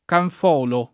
[ kanf 0 lo ]